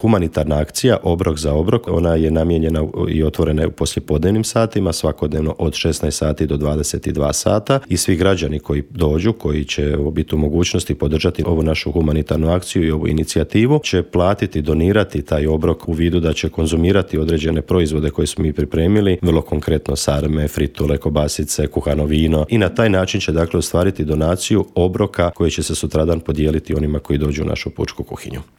u intervjuu Media servisa kako građani mogu sudjelovati u toj humanitarnoj akciji